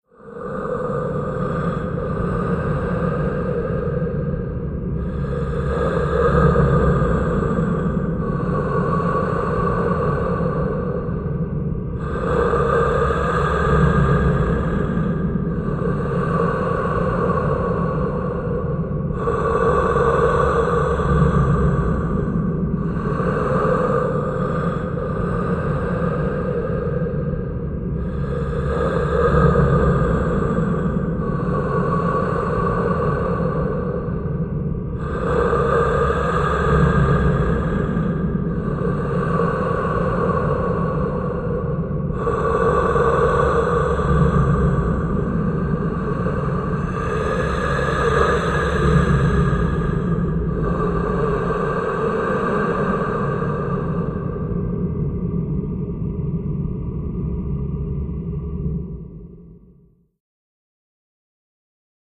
Demon Cave Ambience Cave, Demon, Sci-fi